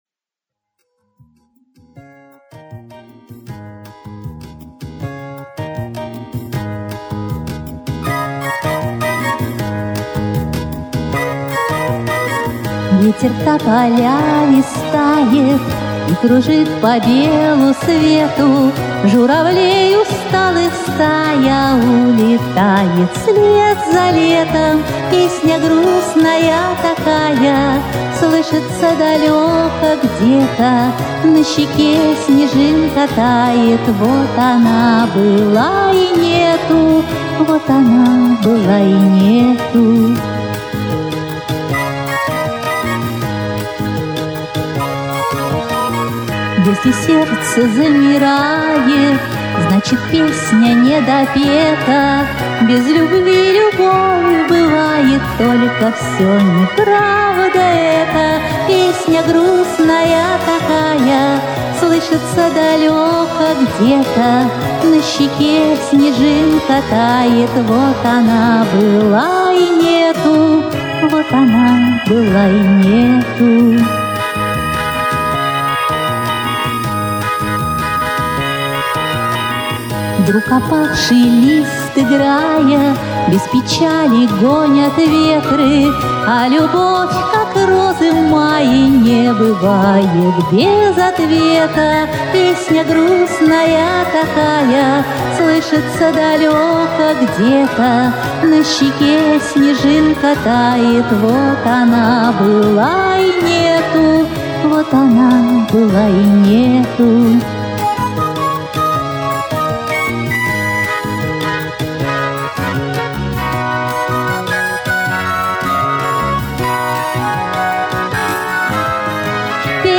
тепло, приятно спела, слушалась хорошо!